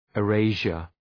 Shkrimi fonetik {ı’reıʃər}